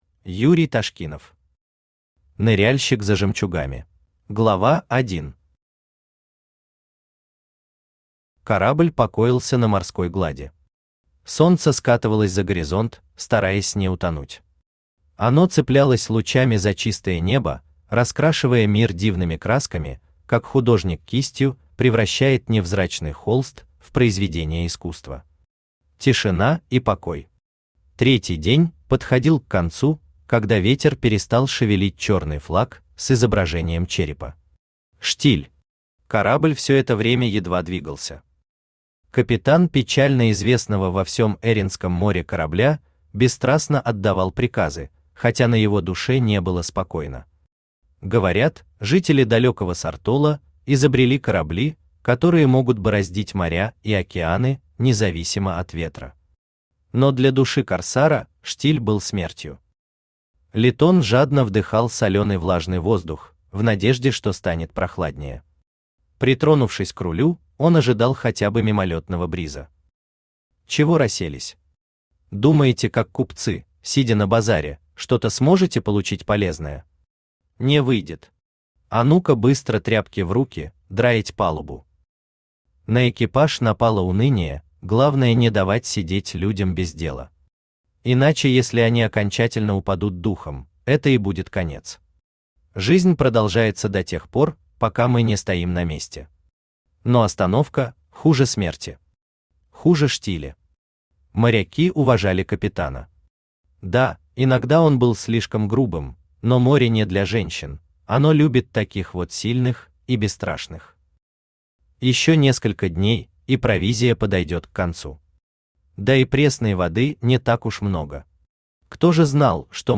Аудиокнига Ныряльщик за жемчугами | Библиотека аудиокниг
Aудиокнига Ныряльщик за жемчугами Автор Юрий Андреевич Ташкинов Читает аудиокнигу Авточтец ЛитРес.